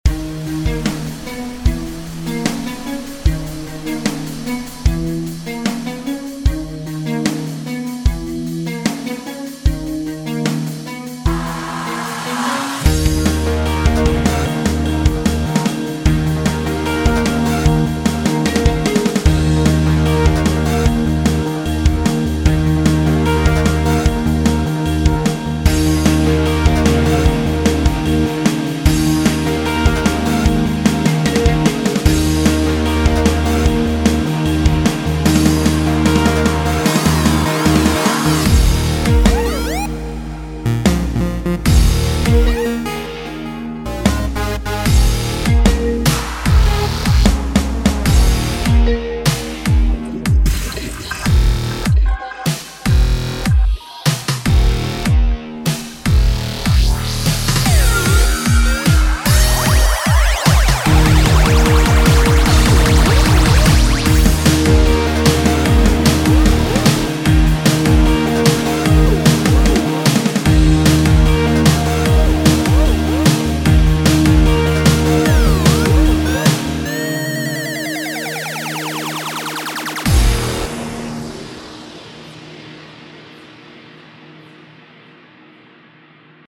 Cinematic theme example
Пример саундтрека. Активная сцена, смешение стилей. Непредсказуемость.